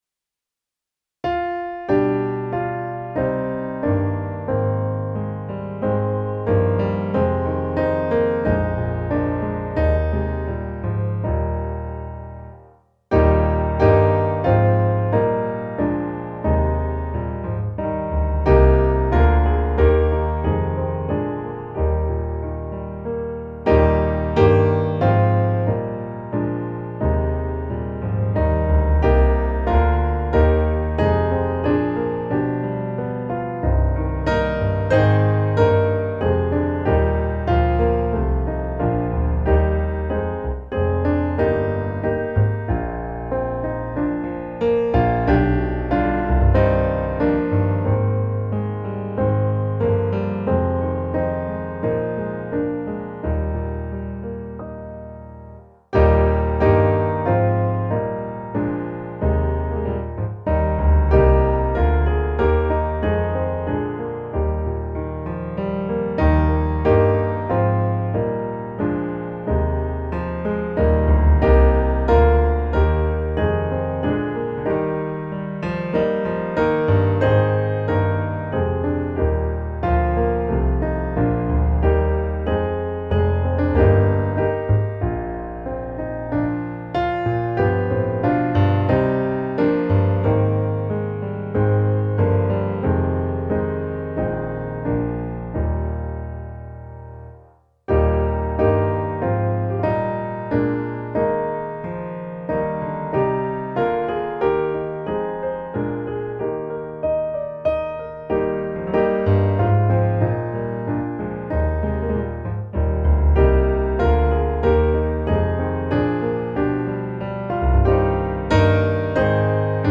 Den blomstertid nu kommer - musikbakgrund
Musikbakgrund Psalm